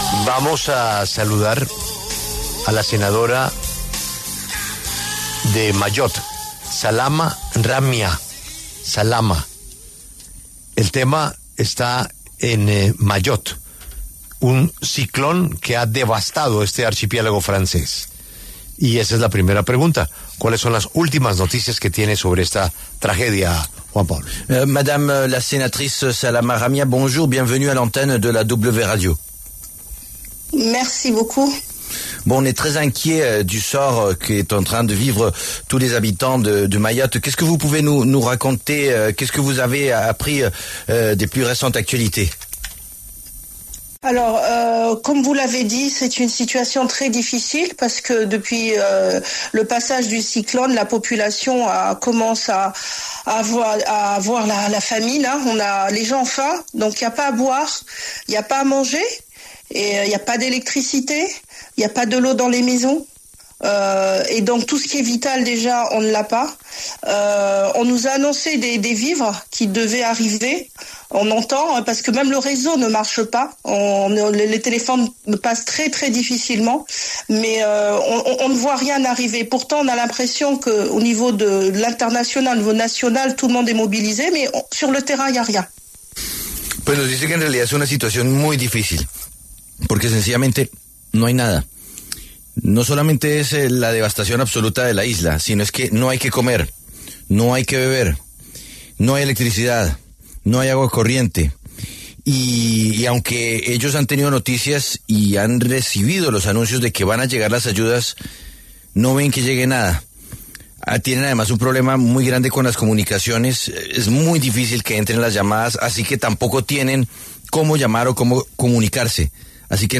En diálogo con La W, Salama Ramia, senadora de Mayotte por el partido Renaissance, dio detalles de cómo está la situación en la isla francesa tras el paso del ciclón Chido que generó graves daños a la infraestructura de la población.